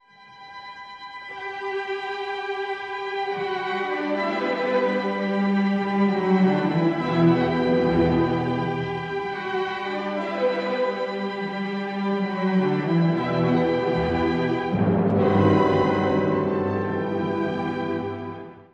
とても雄大で、神秘的、ある意味幻想的な曲です。
より直接的な感情表現や、音色の豊かさ・幅広さを楽しむ曲になっています。